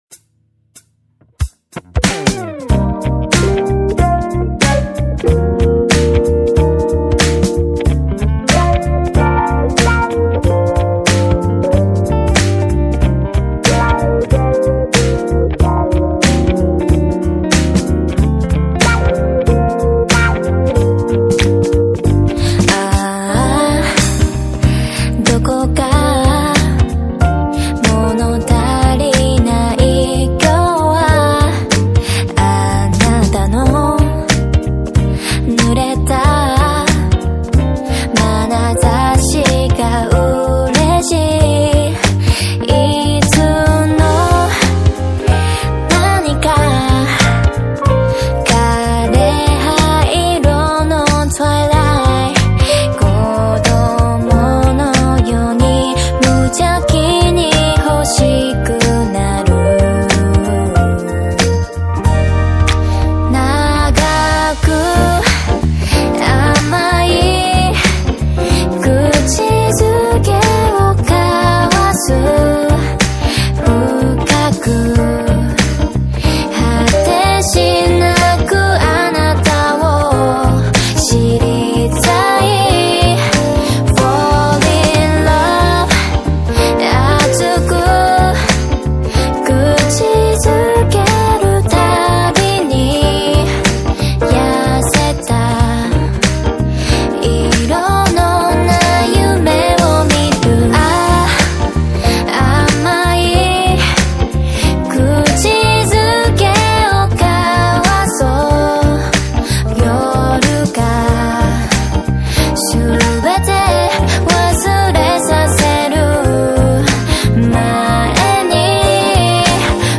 KPop
Label Dance